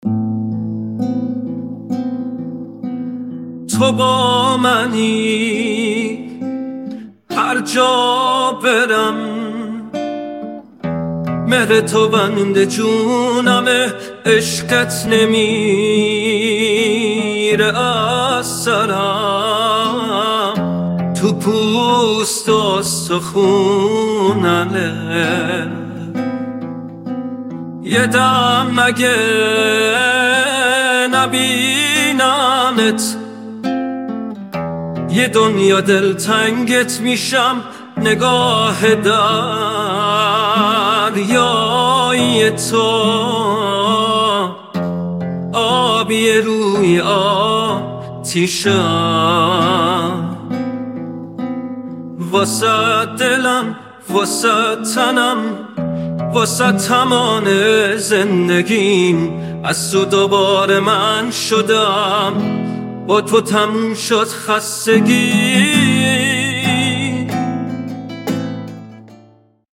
این آهنگ با هوش مصنوعی ساخته شده است